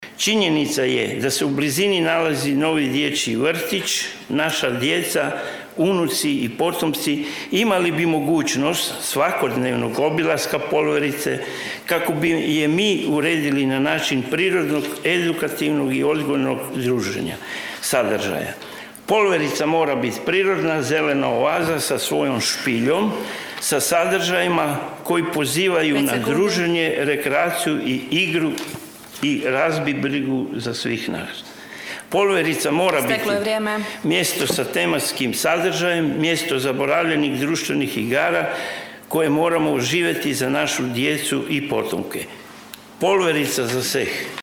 Na Aktualnom satu jučerašnje sjednice Gradskog vijeća Grada Labina vijećnik s liste MOŽEMO!, Mladen Bastijanić, pokrenuo je pitanje revitalizacije Polverice, simbola Vineža, pozivajući na zaštitu i uređenje lokaliteta koji je godinama bio zapostavljen unutar industrijske zone.